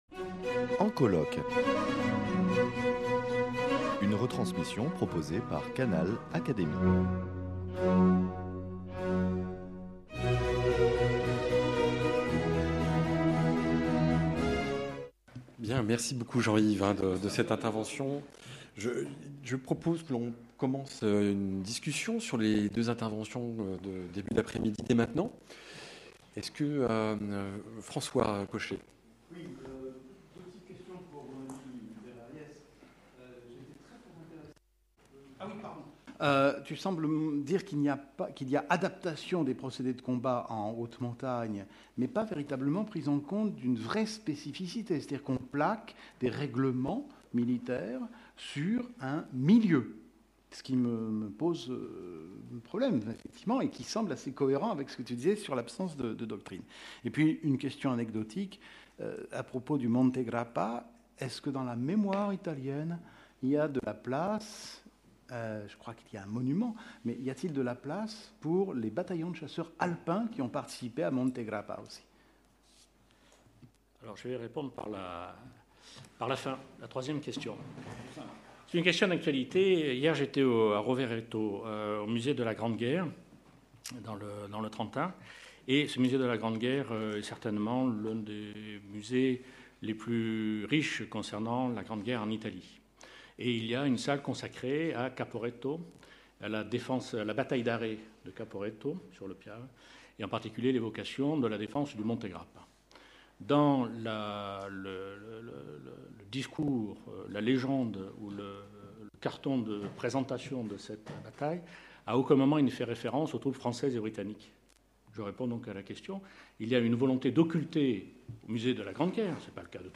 Retransmission des journées d’études « Guerre et géographie » - Partie 4
Débat autour des 2 communications :« La guerre “blanche” des Italiens durant la Grande Guerre ou les enjeux complexes d’une guerre en montagne : adaptation, exp